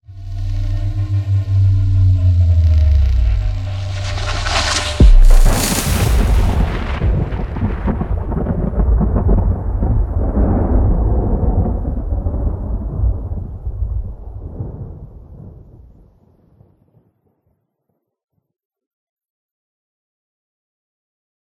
emi_blowout.ogg